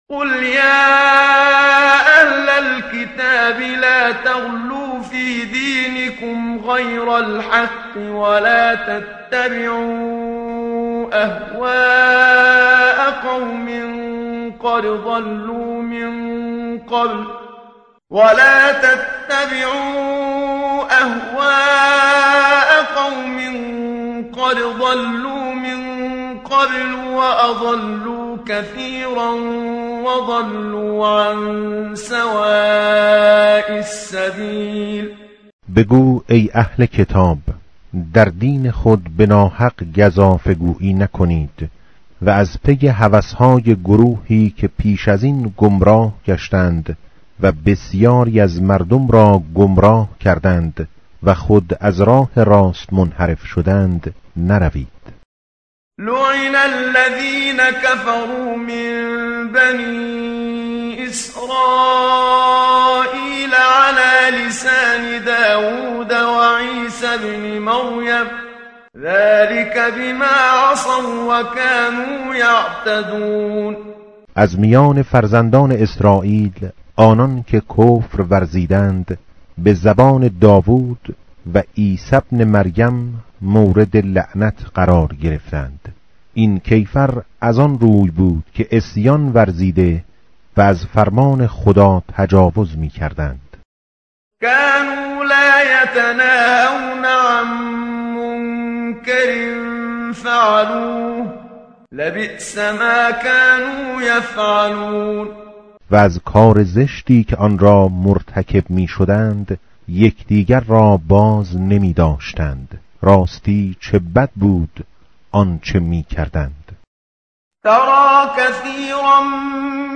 متن قرآن همراه باتلاوت قرآن و ترجمه
tartil_menshavi va tarjome_Page_121.mp3